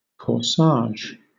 Ääntäminen
Southern England: IPA : /kɔːˈsɑːʒ/